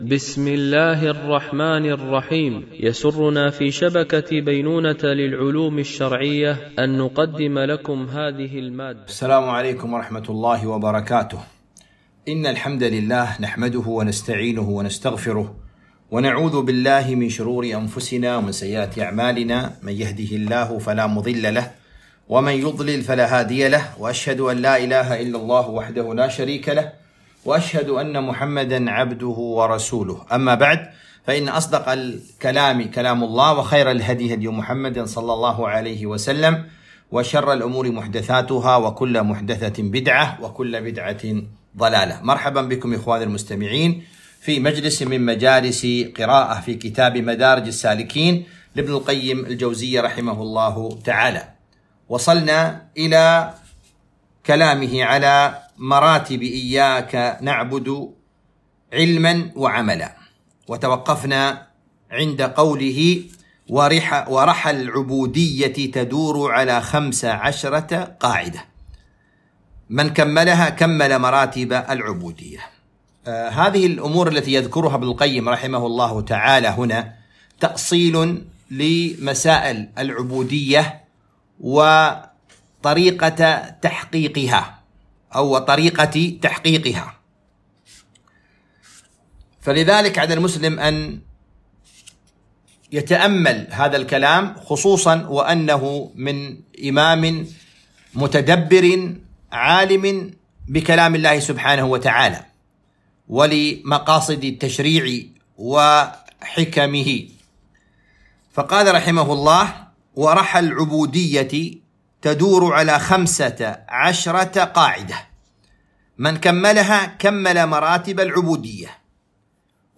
قراءة من كتاب مدارج السالكين - الدرس 16